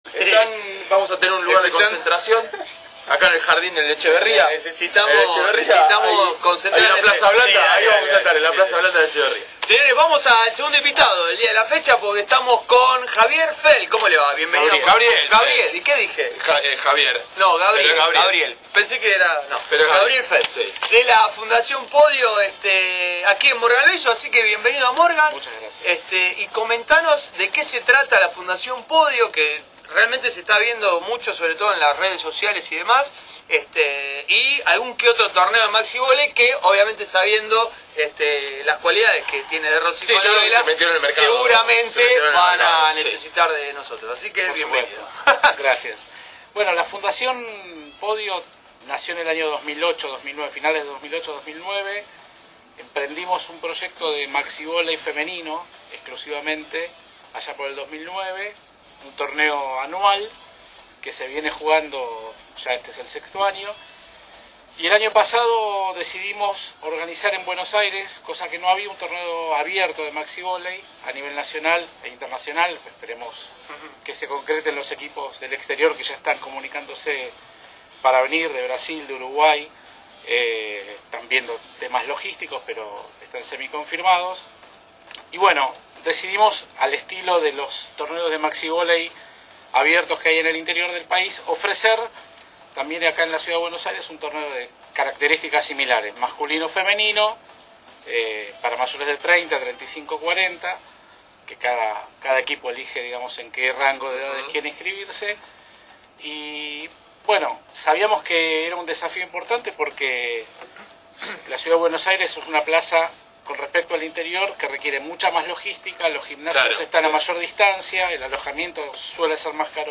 El lunes 28 de julio de 2014, estuvimos presentes en el programa de radio dedicado al deporte